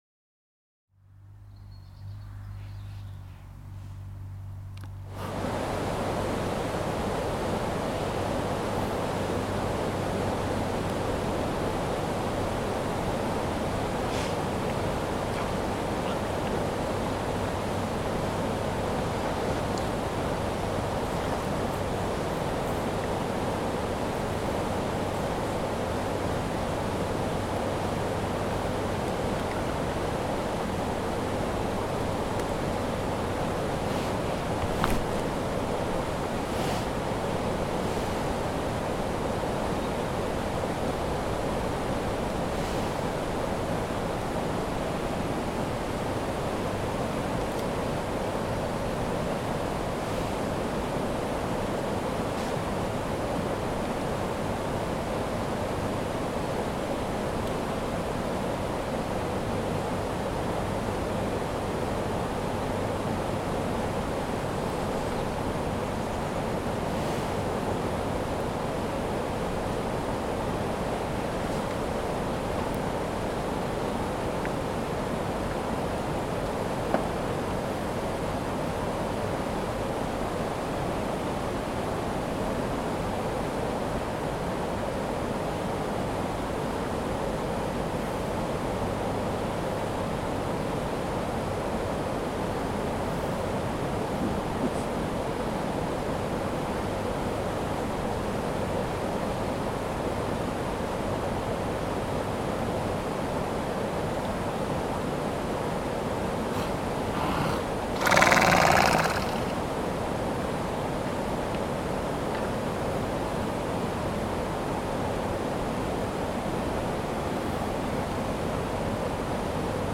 I've take an additive approach; the white noise of the weir on the River Po with those of equine breaths and freshets at the Hydro near Blarney, Co. Cork, Ireland."